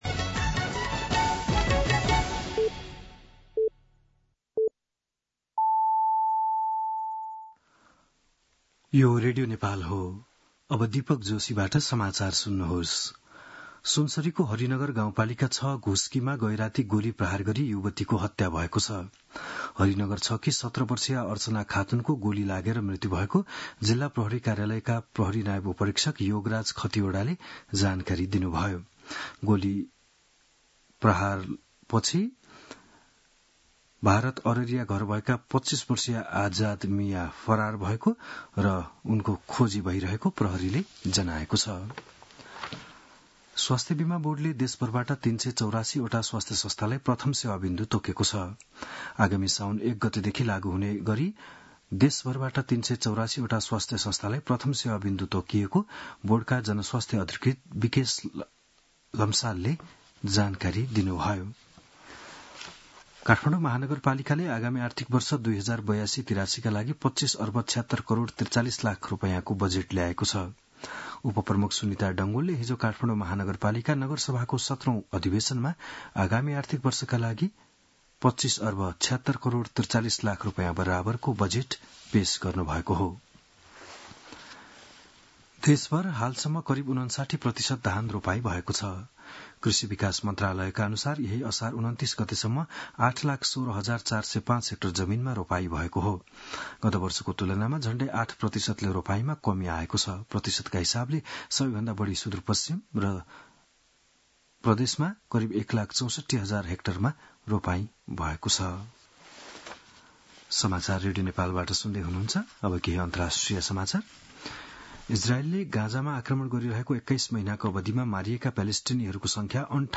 बिहान ११ बजेको नेपाली समाचार : १८ पुष , २०२६
11-am-News-3-30.mp3